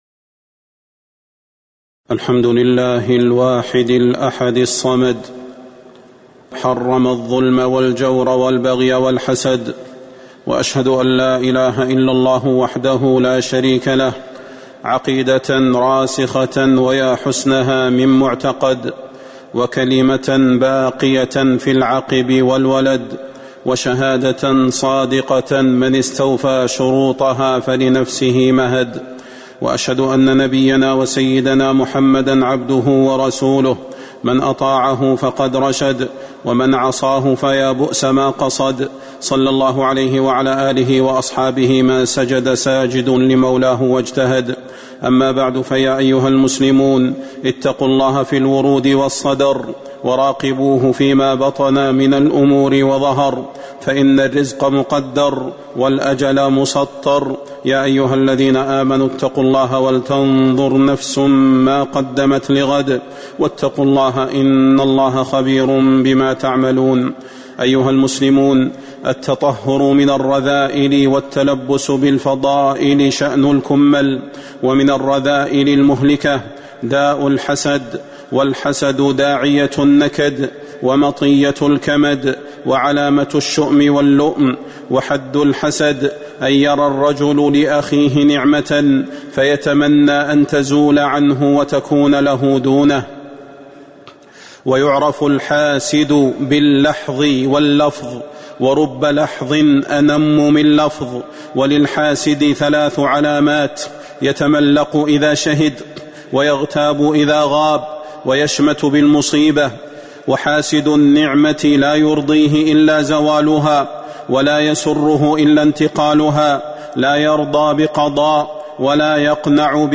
تاريخ النشر ١٧ ربيع الثاني ١٤٤٤ هـ المكان: المسجد النبوي الشيخ: فضيلة الشيخ د. صلاح بن محمد البدير فضيلة الشيخ د. صلاح بن محمد البدير القول السدد في ذم الحسد